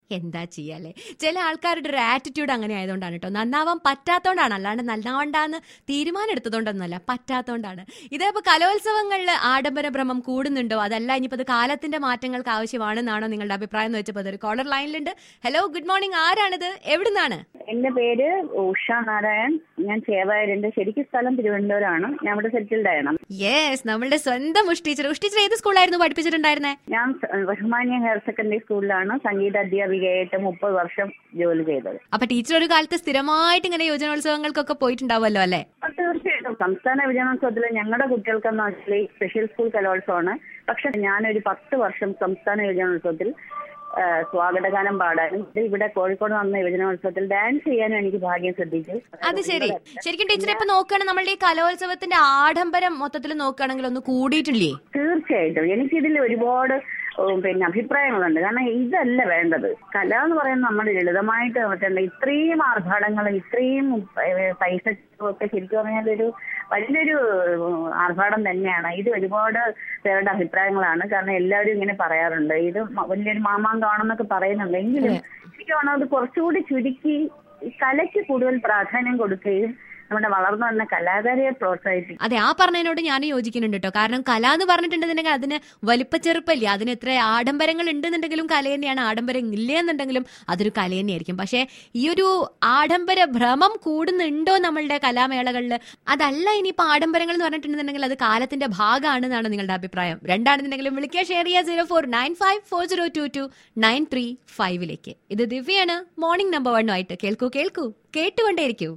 CALLER ON INCREASING EXTRAVAGENZA OF YOUTH FESTIVALS.